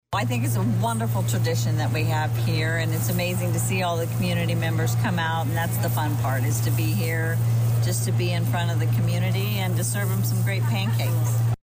The David S. Palmer Arena was the place to be for Thursday’s 74th Kiwanis Pancake Day in Danville.